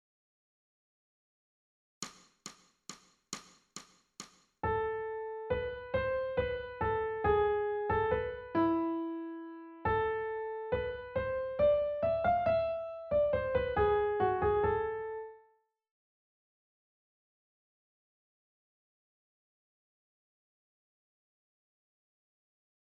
ソルフェージュ 聴音: 1-iii-10